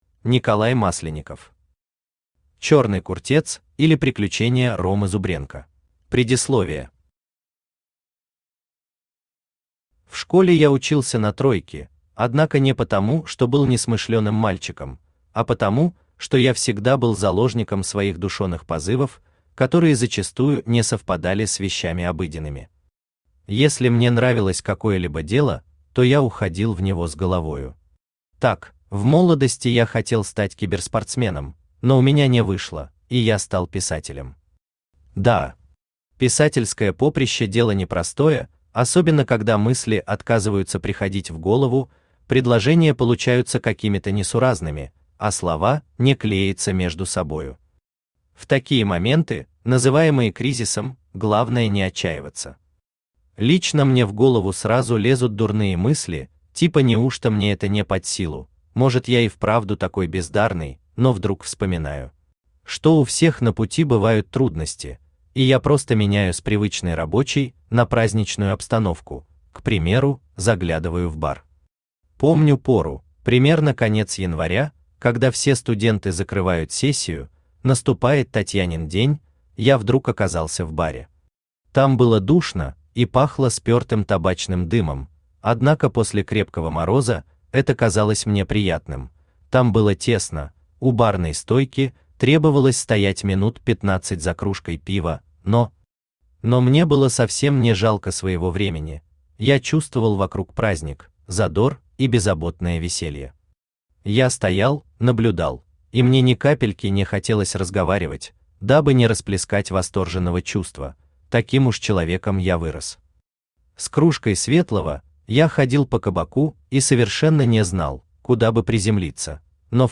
Аудиокнига Черный куртец или приключения Ромы Зубренко | Библиотека аудиокниг
Aудиокнига Черный куртец или приключения Ромы Зубренко Автор Николай Александрович Масленников Читает аудиокнигу Авточтец ЛитРес.